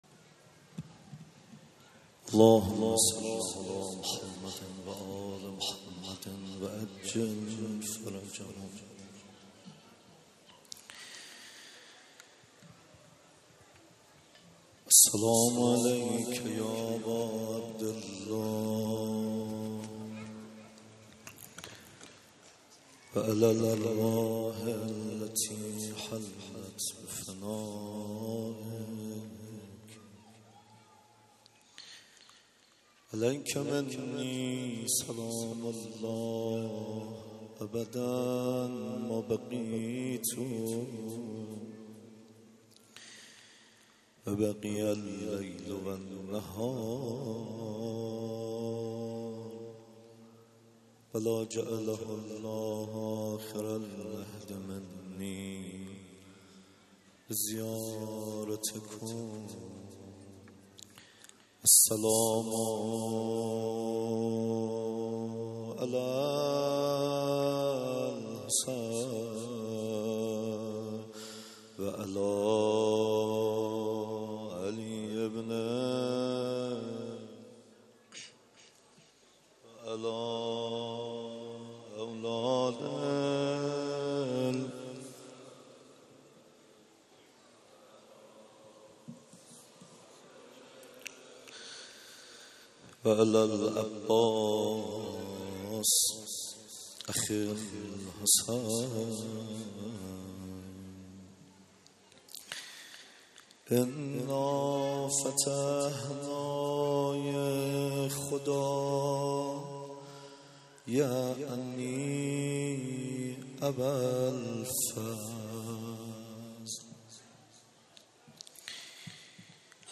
روضه روز تاسوعا